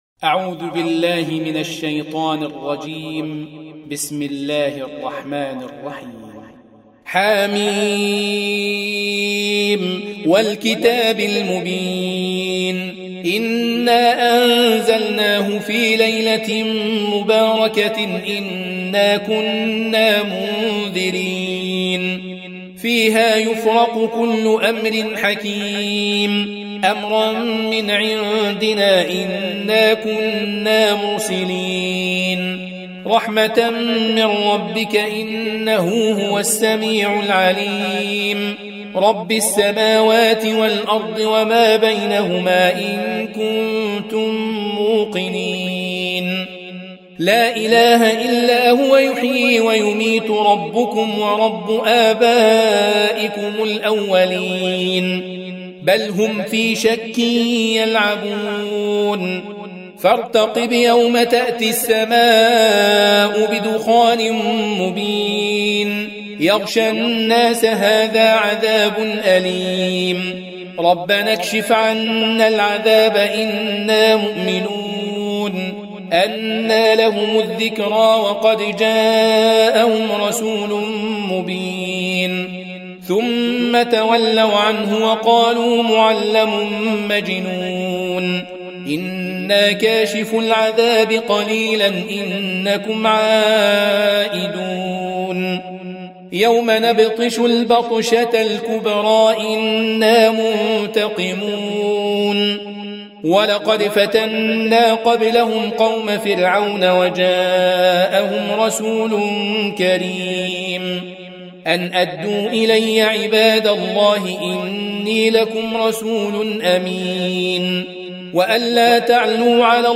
Audio Quran Tarteel Recitation
Surah Repeating تكرار السورة Download Surah حمّل السورة Reciting Murattalah Audio for 44. Surah Ad-Dukh�n سورة الدّخان N.B *Surah Includes Al-Basmalah Reciters Sequents تتابع التلاوات Reciters Repeats تكرار التلاوات